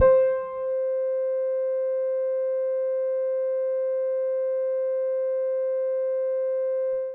世界中で多くの人に愛されたサンプリングキーボード SK-1
◆Piano